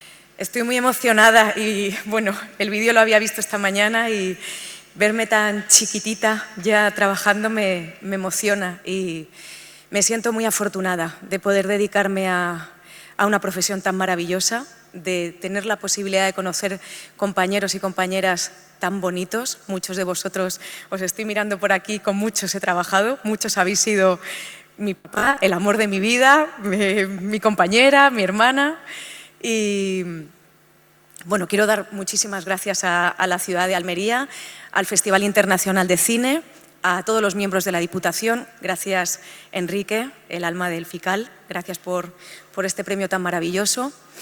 La actriz ha recibido el premio Almería Tierra de Cine de manos del presidente de la Diputación, Javier A. García, en una ceremonia con música, emoción y el brillo de los protagonistas de ‘La Boda’ y ‘Papeles’
14-11_fical_gala_inaugural_premio_adriana_ugarte.mp3